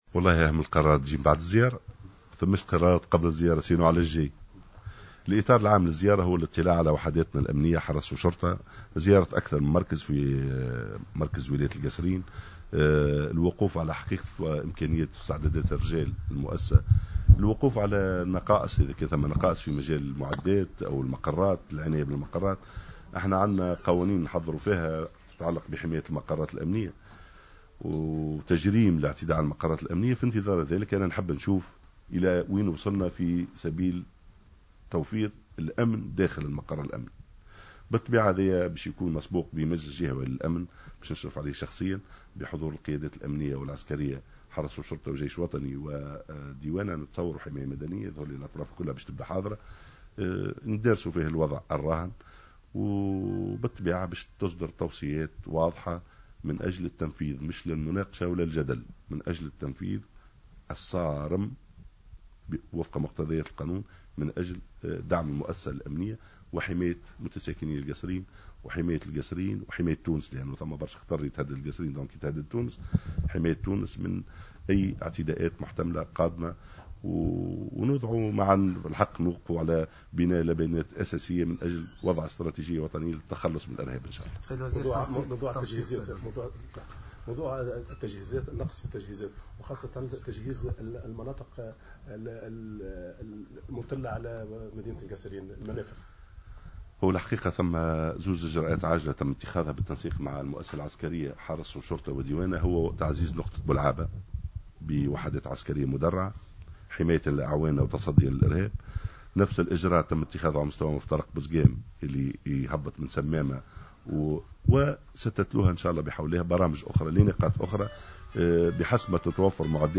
وأكد الوزير في تصريح